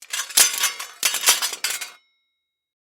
Sword Drops
Sword Drops is a free sfx sound effect available for download in MP3 format.
yt_yAPUbzja2j8_sword_drops.mp3